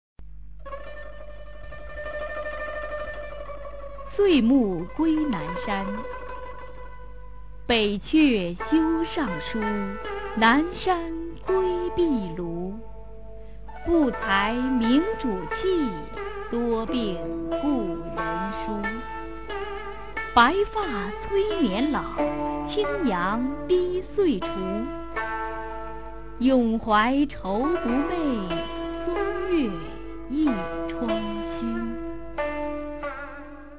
[隋唐诗词诵读]孟浩然-岁暮归南山a 配乐诗朗诵